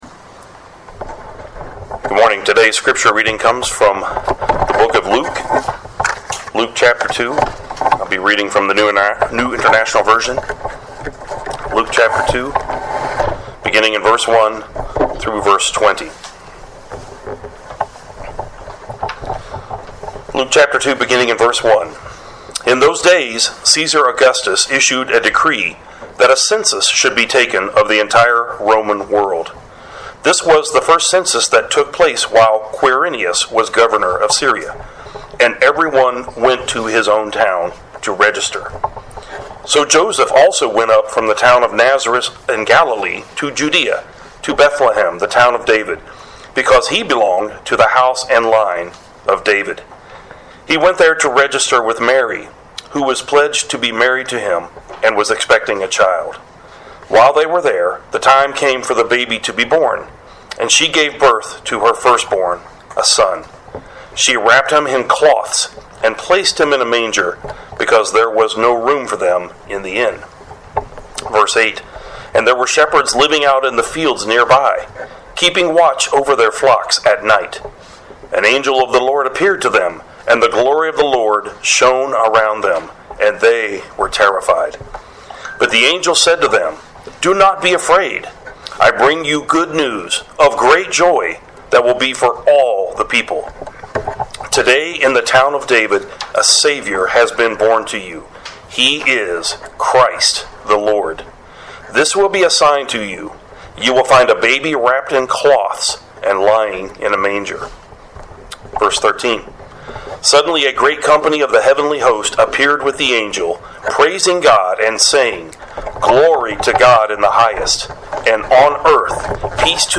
Sermons | Three Mile Wesleyan Church